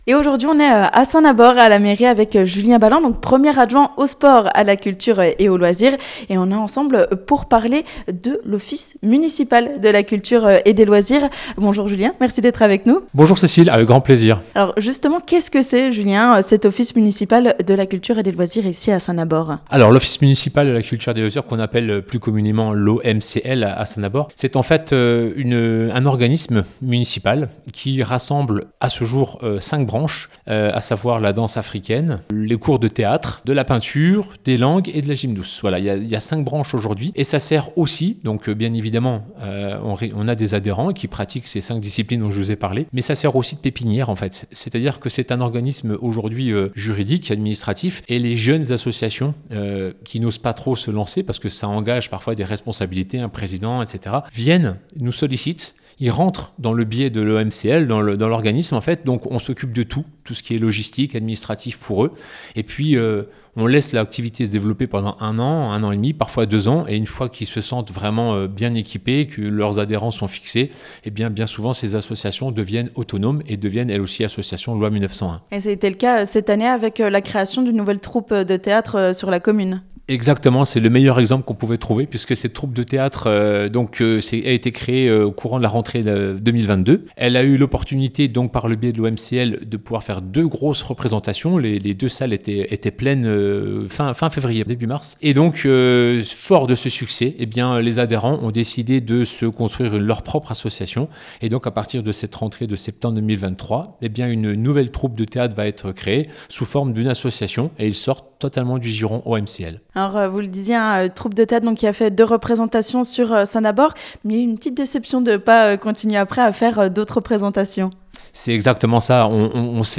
Et bonne nouvelle pour les amateurs de théatre du secteur, un partenariat est né entre les communes de Saint Nabord, Remiremont et Saint Etienne les Remiremont : les troupes de théâtre pourront jouer dans les 3 communes. Pour en savoir plus, écoutez l'interview en podcast de Julien Balland - 1er adjoint au sport, à la culture et aux loisirs - Mairie Saint-Nabord.